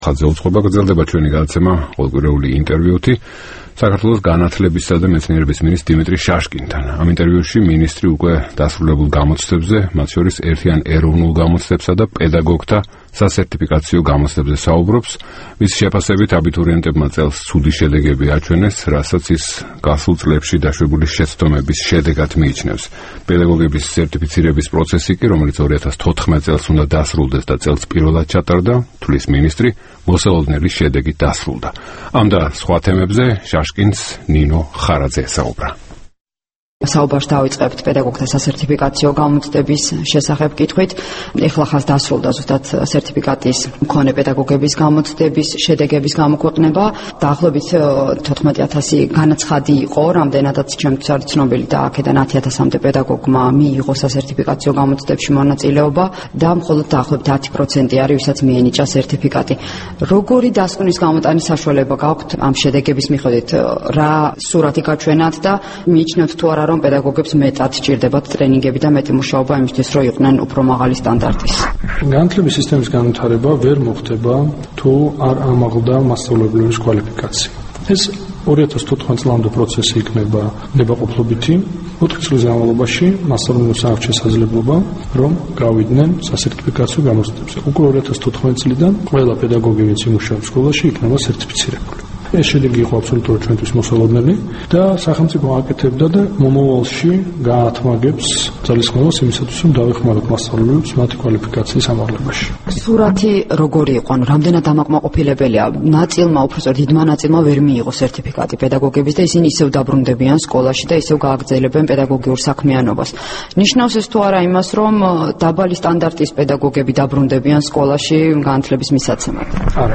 საქართველოს განათლებისა და მეცნიერების მინისტრი დიმიტრი შაშკინი დასრულებულ გამოცდებზე საუბრობს